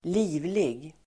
Uttal: [²l'i:vlig]